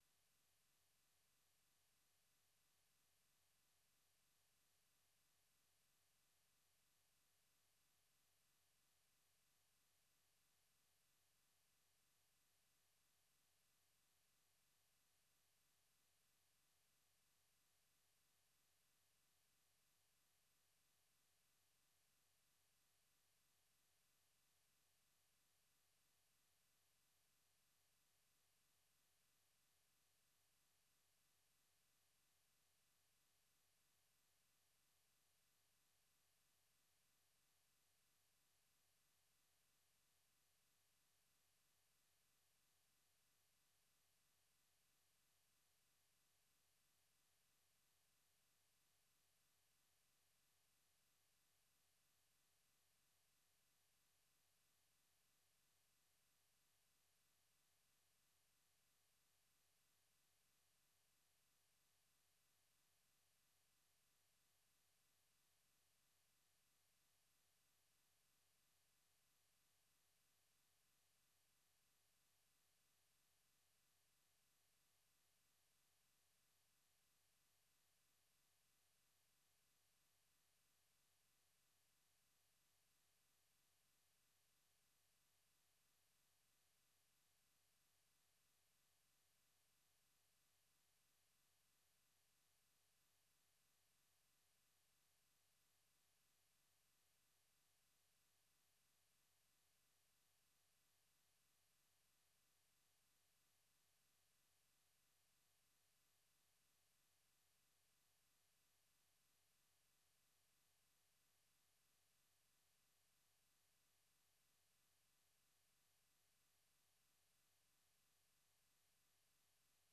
Daybreak Africa is a 30-minute breakfast show looks at the latest developments on the continent and provides in-depth interviews, and reports from VOA correspondents.